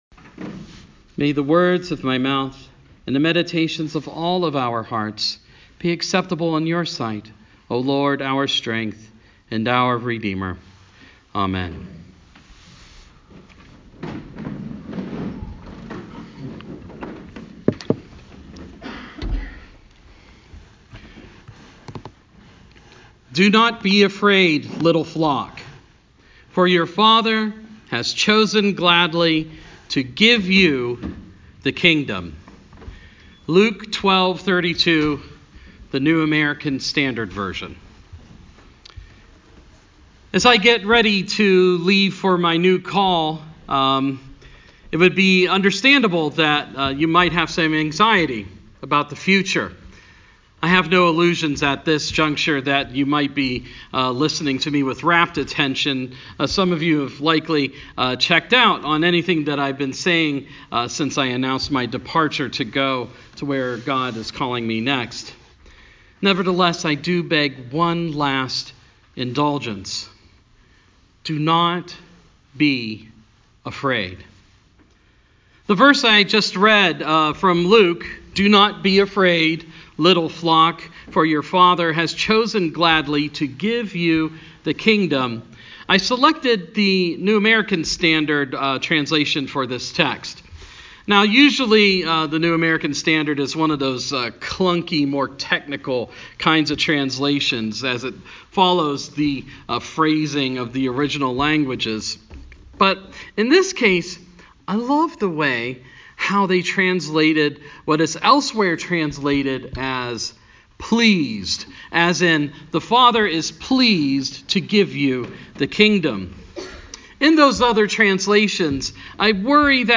Goodbye Sermon 2019